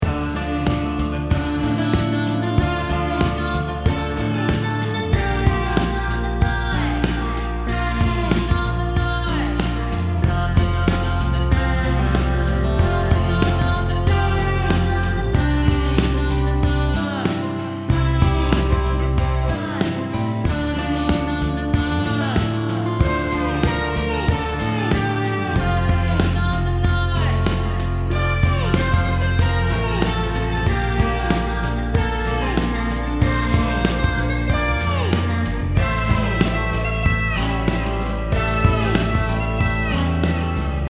528hz BPM90-99 calm Game Instrument Soundtrack 穏やか
BPM 94